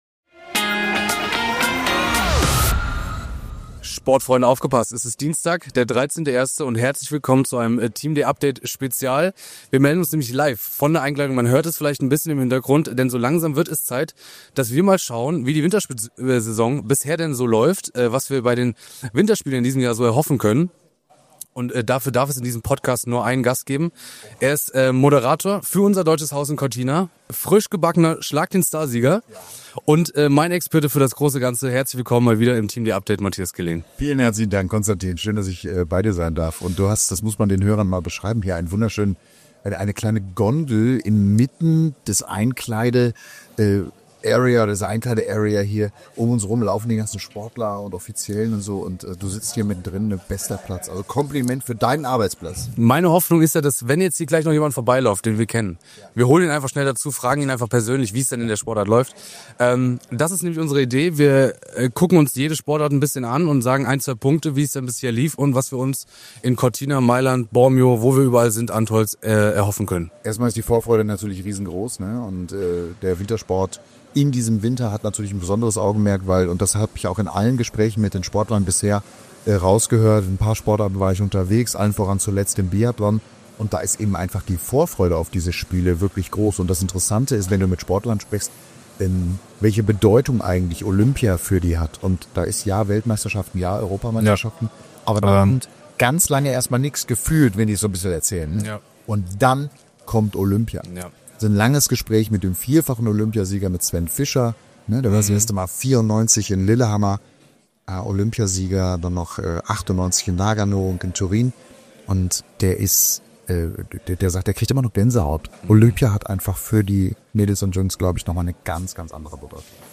Beschreibung vor 3 Monaten Wir melden uns LIVE von der Einkleidung, denn so langsam wird es Zeit, dass wir mal schauen, wie die Wintersport Saison bisher läuft und was wir uns bei den Winterspielen in diesem Jahr so erhoffen können. Dafür darf es in diesem Podcast nur einen Gast geben: Er ist Moderator für unser Deutsches Haus in Cortina, frisch gebackener Schlag den Star-Sieger und mein Experte für das große Ganze: Matthias Killing Mehr